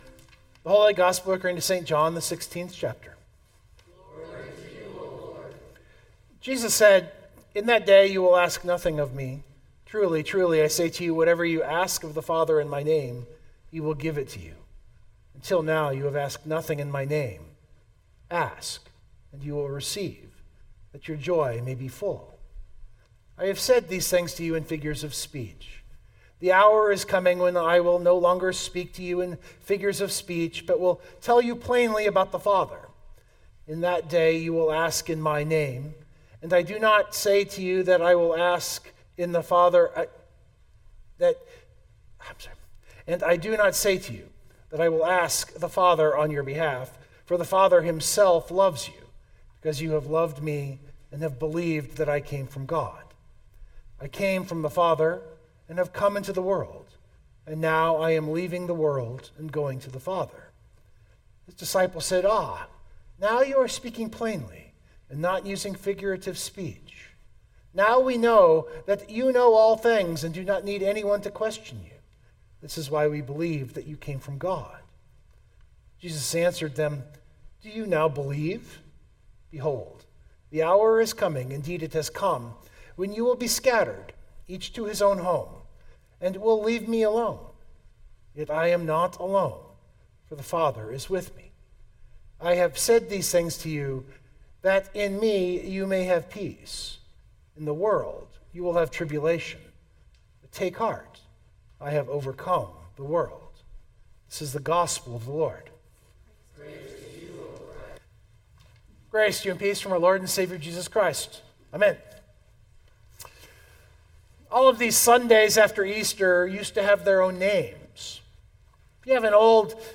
052525 Sermon Download Biblical Text: John 16:23-33 On our current calendar it was the 6th Sunday in Easter.